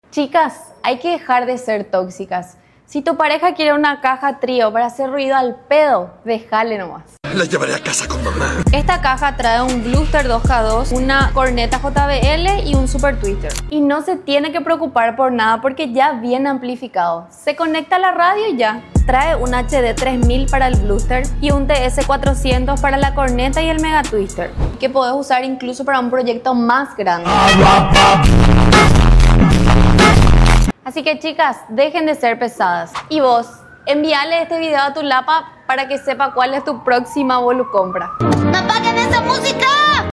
esta caja acústica trío está diseñada para los que buscan un golpe seco y contundente.
ofrece un sonido limpio y agresivo